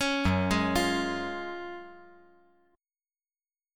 F#M7sus2 Chord
Listen to F#M7sus2 strummed